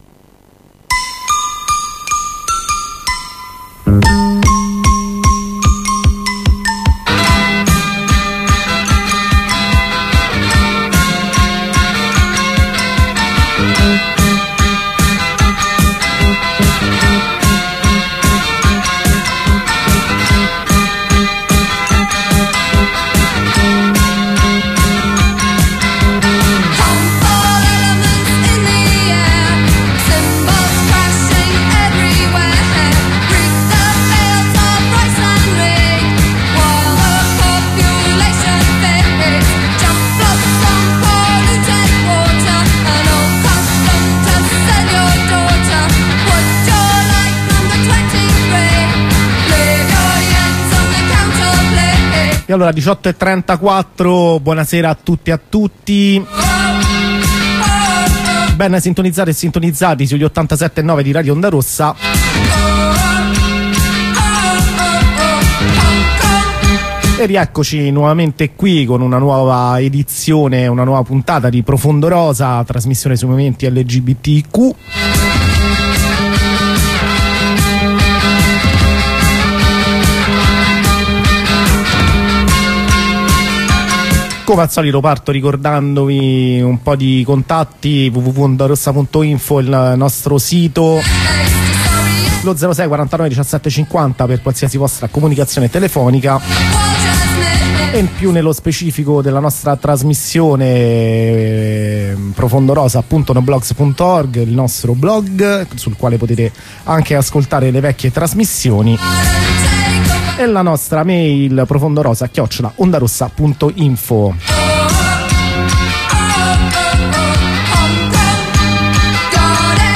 Redazionale con le attiviste